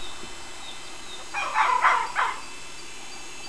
Si tratta di un animale solitario, che comunica con i conspecifici solo a distanza, attraverso segnali sonori ed olfattivi.
fox.wav